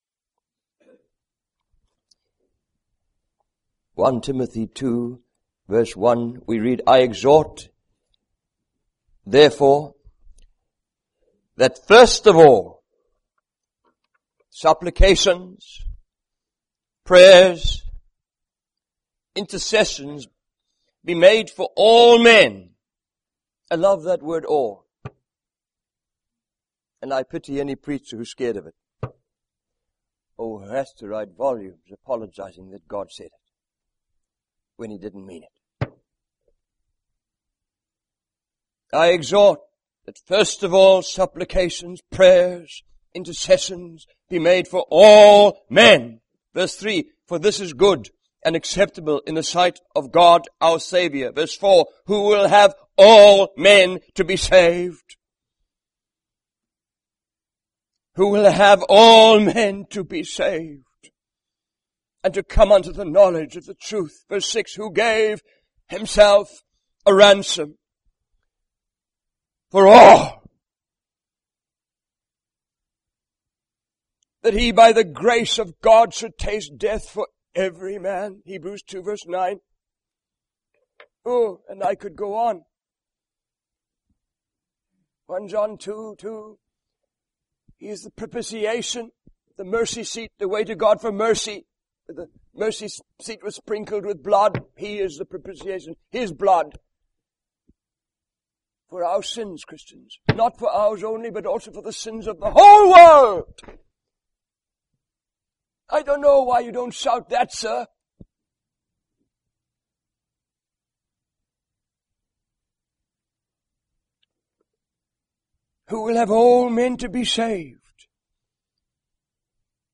In this sermon, the speaker shares a powerful testimony of a woman who was equipped with God's word and had a burning passion to share it with others.